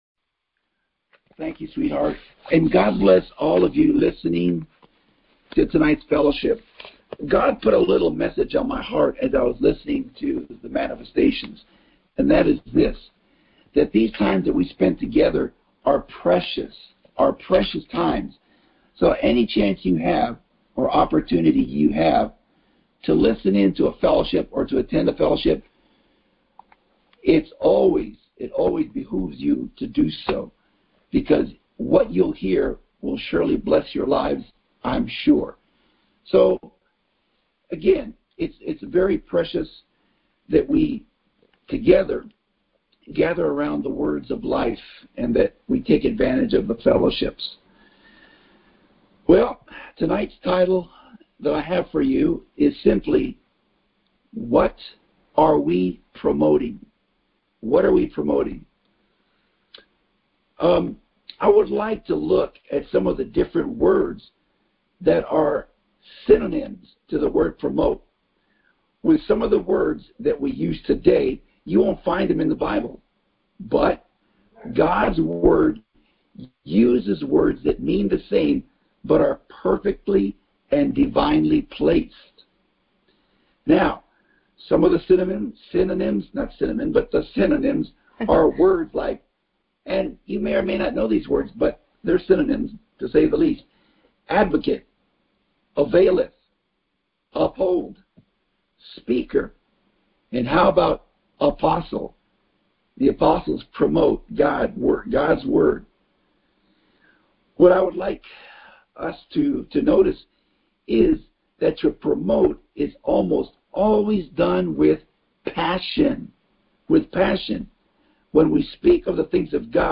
What Are We Promoting? Details Series: Conference Call Fellowship Date: Monday, 02 November 2020 Hits: 591 Play the sermon Download Audio ( 3.95 MB )